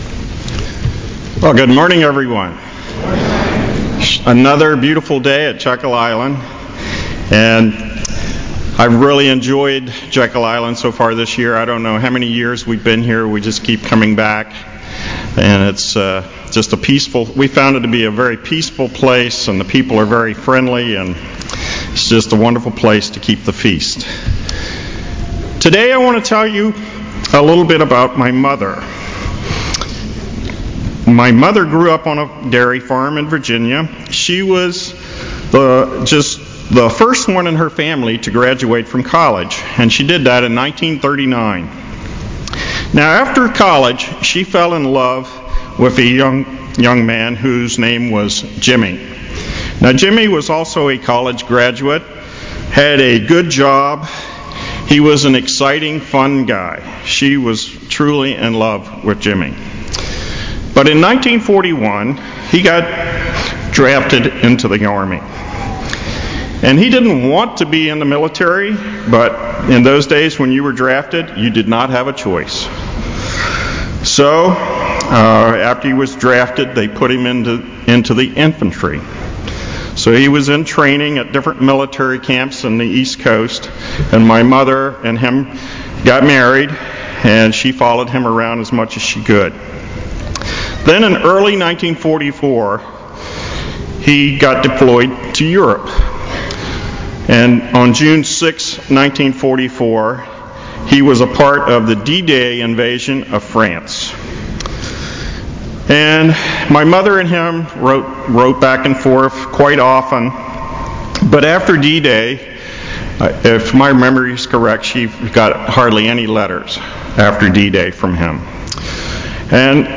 This sermon was given at the Jekyll Island, Georgia 2017 Feast site.